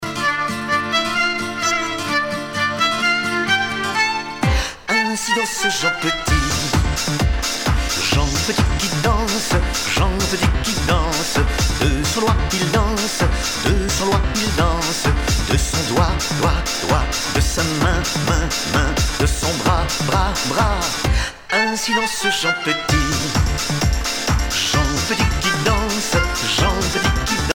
Couplets à danser